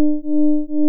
そのために、フェードイン・フェードアウトの種類の1種である、コサインイン・コサインアウトを用います。
オリジナルをコサインアウトし、
複製を上下前後反転してコサインインして、つなぐ形になります。